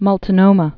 (mŭlt-nōmə)